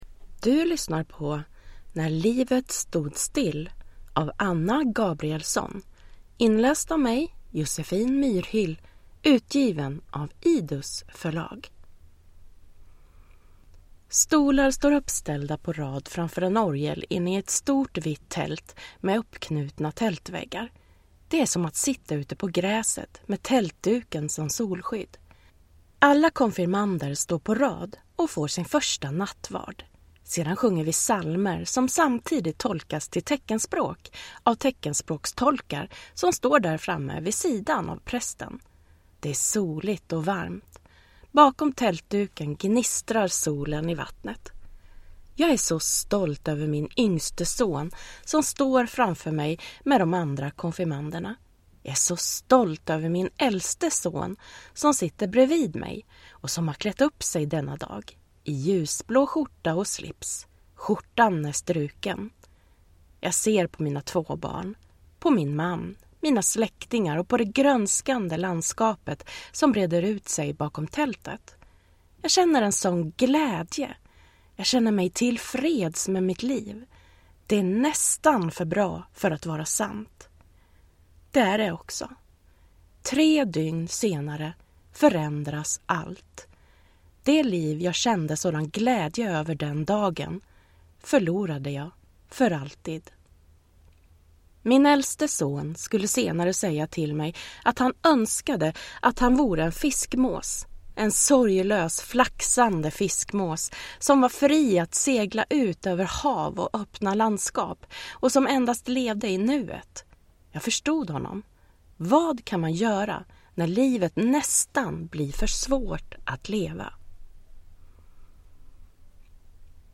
När livet stod still – Ljudbok